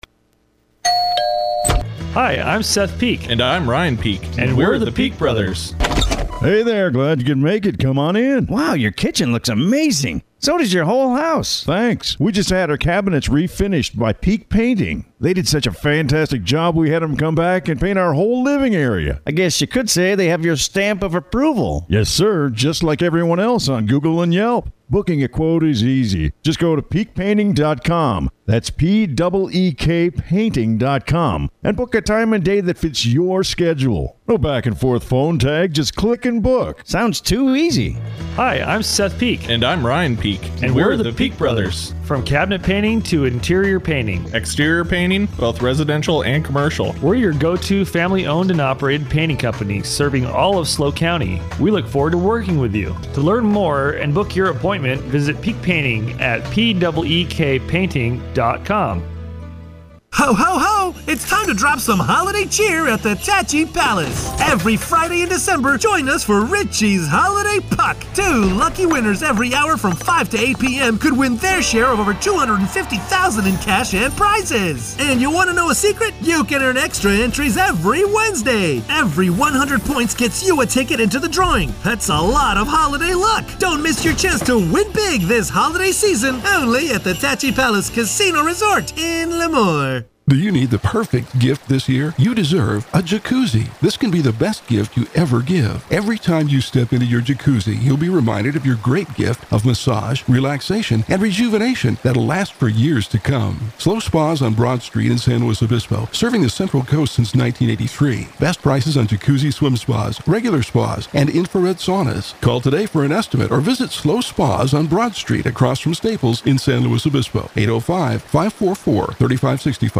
The Morning Exchange; North County’s local news show airs 6 a.m. to 9 a.m. every weekday.